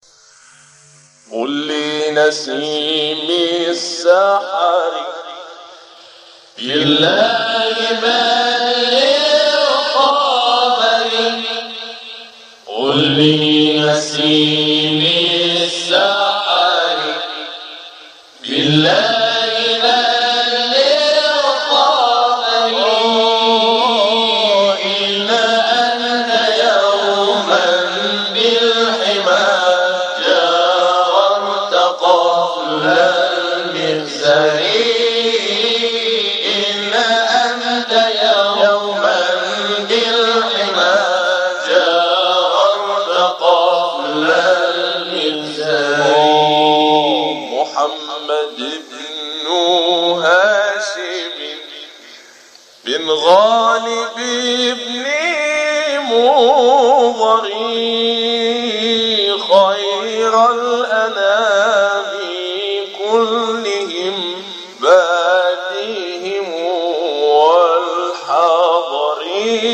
مقام النهاوند ( تواشیح ) قل لنسیم السحر - لحفظ الملف في مجلد خاص اضغط بالزر الأيمن هنا ثم اختر (حفظ الهدف باسم - Save Target As) واختر المكان المناسب